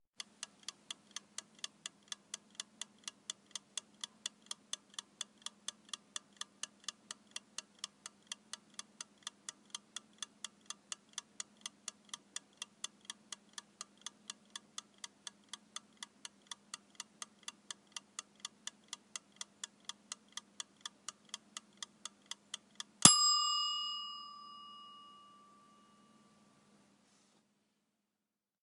timer with ding
bell ding egg-timer tick ticking timer timer-bell sound effect free sound royalty free Sound Effects